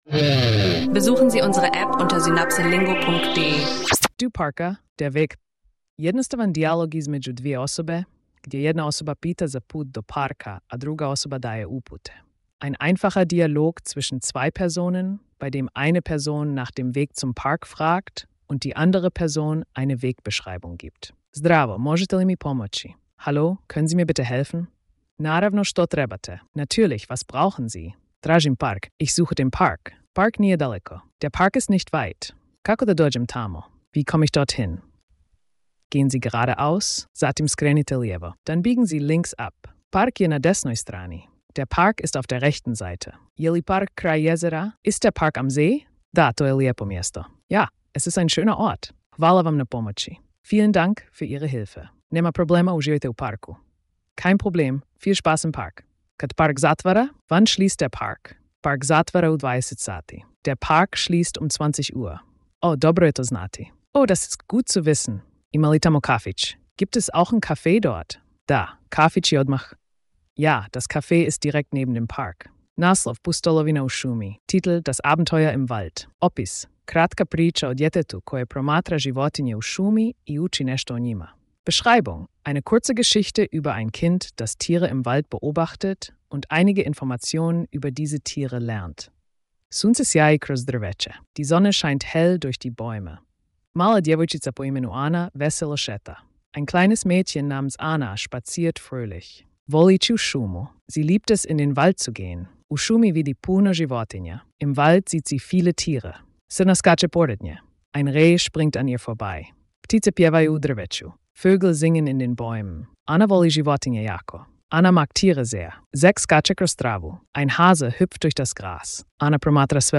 Diese Episode stellt einen einfachen Dialog zum Thema 'Wegbeschreibung' vor und integriert Vokabeln wie 'Park' und 'Gehen'. Optimal für Anfänger, die ihre Sprachkenntnisse für den Alltag verbessern möchten.